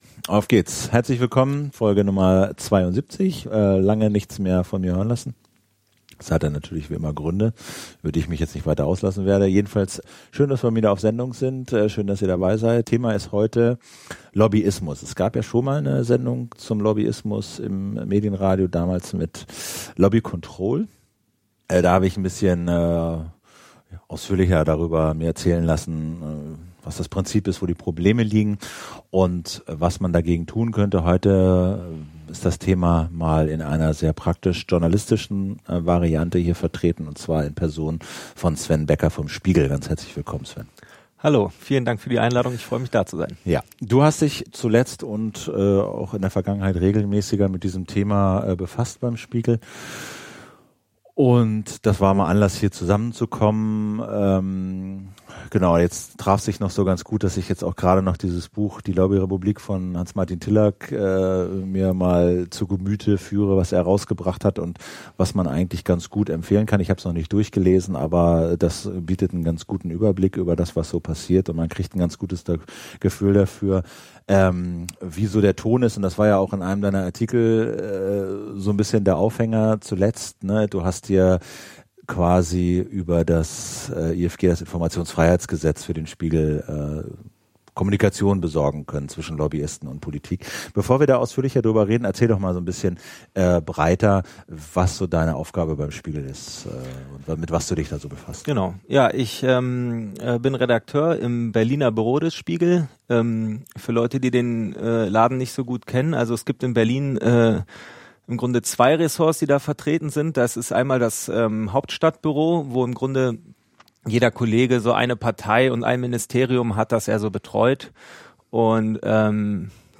ein empfehlenswertes rund 95 Minuten langes Gespräch über Lobbyismus, Public-Private-Partnerships und das Informationsfreiheitsgesetz.